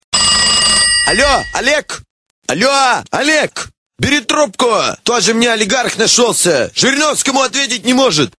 Главная » Рингтоны » Рингтоны пародии